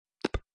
BtnKlik.wav